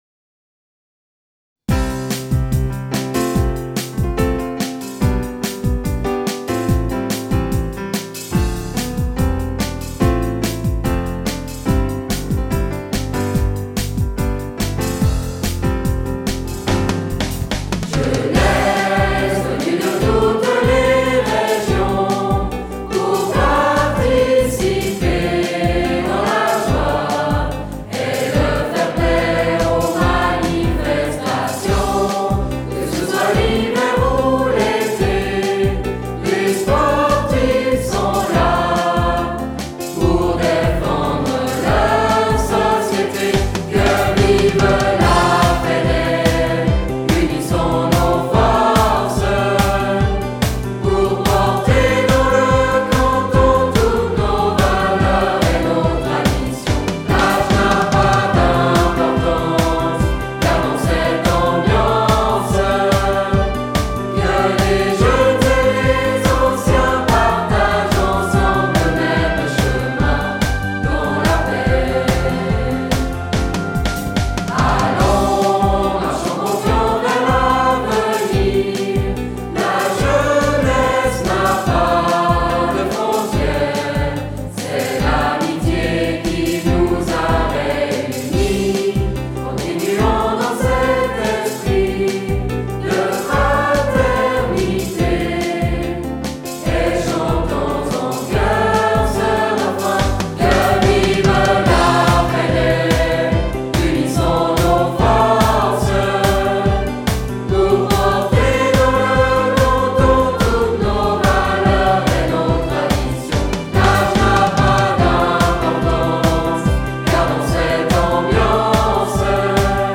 Hymne